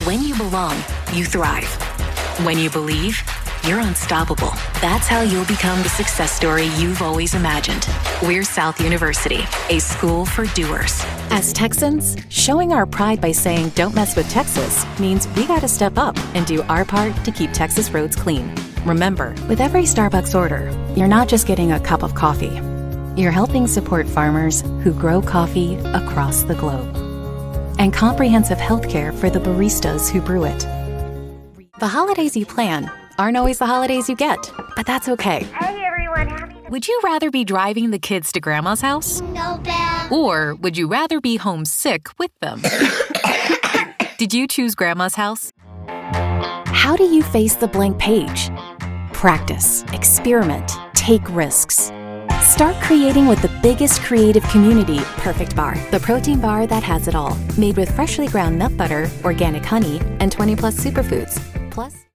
Professional Female Voice Over Talent
2025-Commercial-Demo.mp3